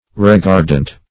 Reguardant \Re*guard"ant\ (r?*g?rd"ant)